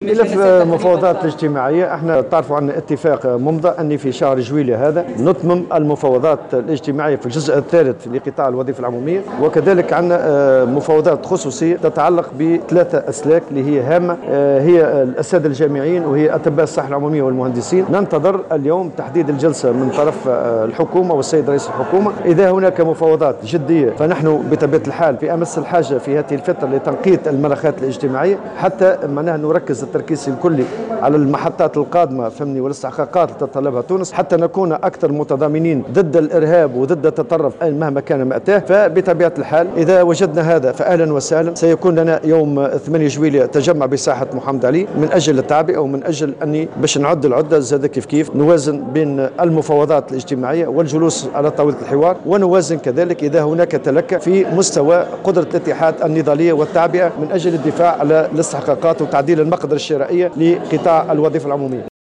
قال الأمين العام للاتحاد العام التونسي للشغل نورالدين الطبوبي، في تصريح لمراسلة الجوهرة أف أم، اليوم الثلاثاء، إن الاتفاق الممضى مع الطرف الحكومي يقضي باستئناف المفاوضات الاجتماعية في قطاع الوظيفة العمومية في جزئها الثالث خلال شهر جويلية.
وأبرز الطبوبي، خلال افتتاح الجلسة العامة الخامسة للشبكة النقابية للهجرة ببلدان المتوسط وجنوب الصحراء، أن هذه المفاوضات ستكون مخصصة بـ3 أسلاك في الوظيفة العمومية، وهي الأساتذة الجامعيون وأطباء الصحة العمومية والمهندسون.